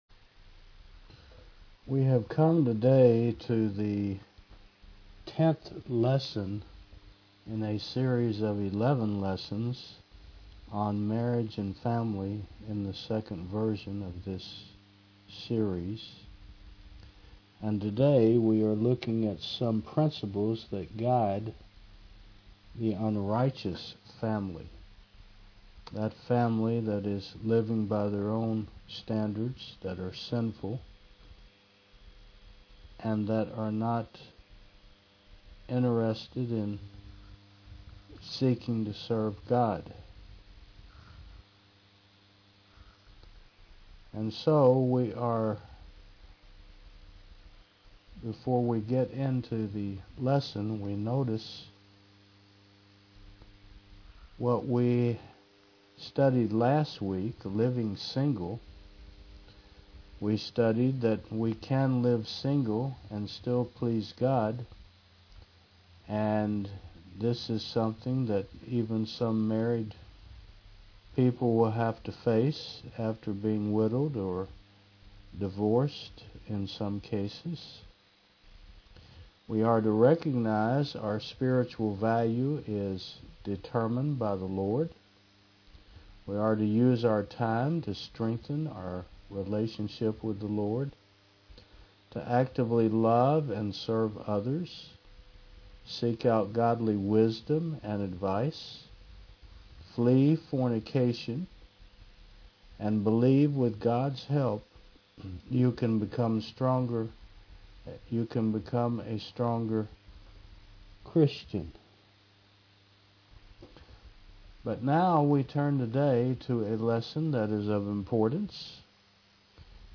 Service Type: Mon. 9 AM